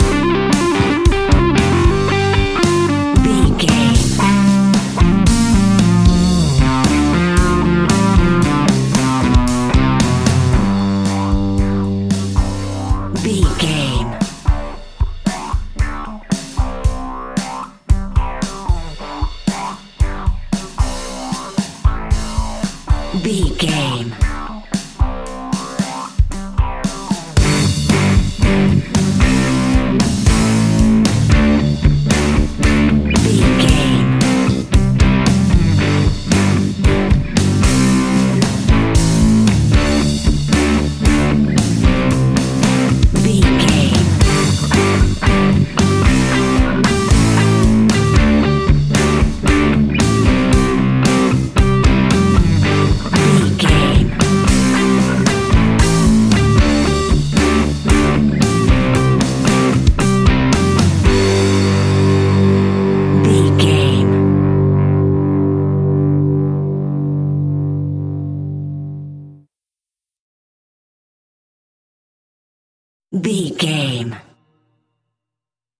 Epic / Action
Fast paced
Ionian/Major
hard rock
blues rock
rock instrumentals
Rock Bass
heavy drums
distorted guitars
hammond organ